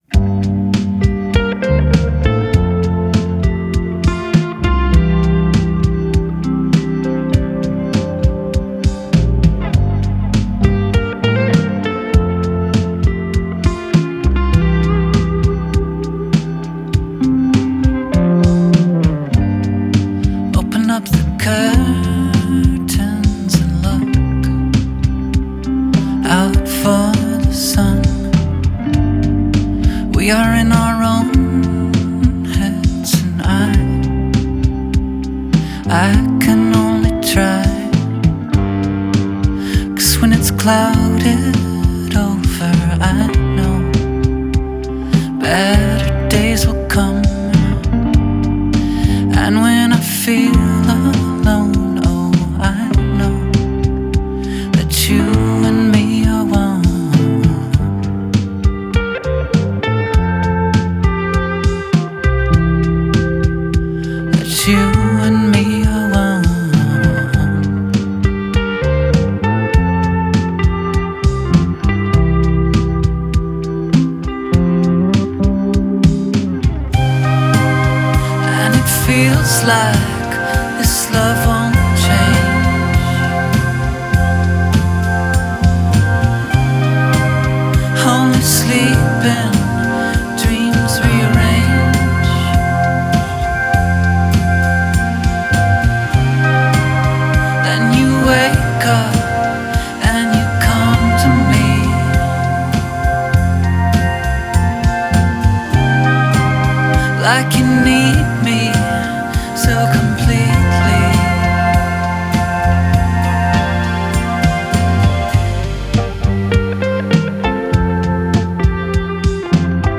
Жанр: Indie.